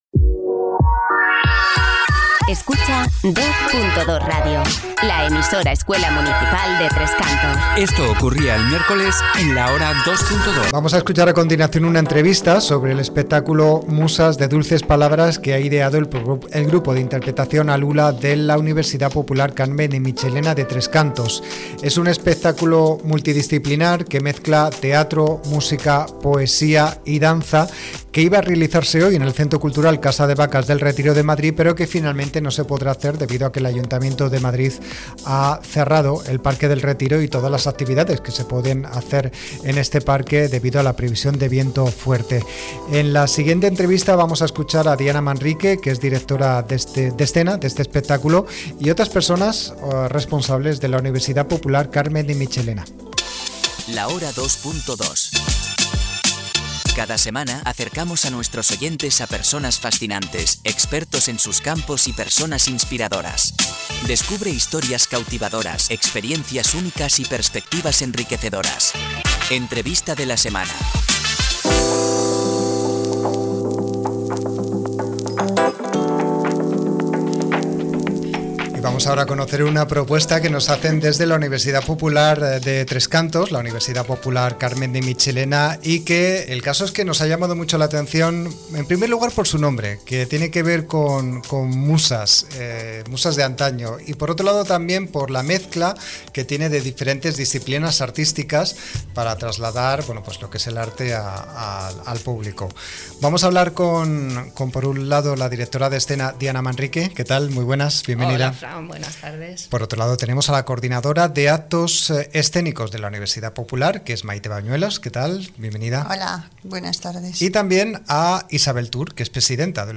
Entrevista-Espectaculo-Musas-de-dulces-palabras-.mp3